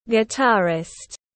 Nghệ sĩ đàn ghi-ta tiếng anh gọi là guitarist, phiên âm tiếng anh đọc là /ɡɪˈtɑːrɪst/.
Guitarist /ɡɪˈtɑːrɪst/